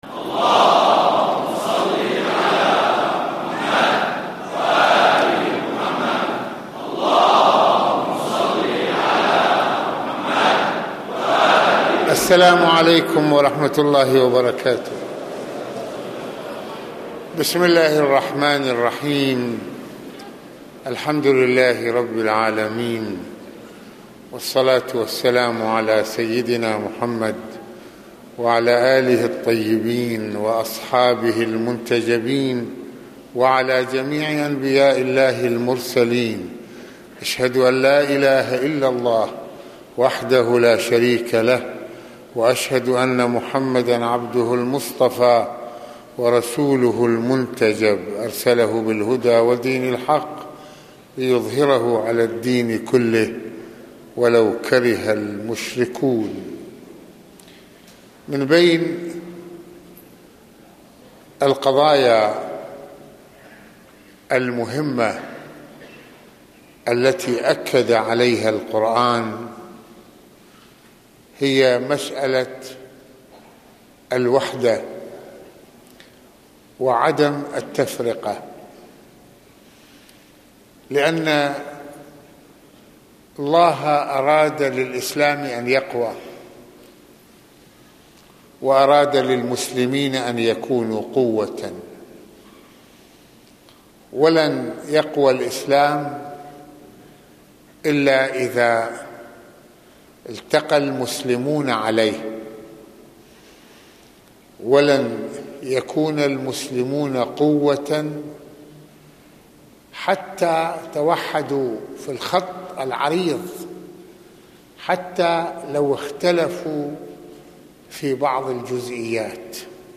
خطبة الجمعة
مسجد الحسنين (ع) - حارة حريك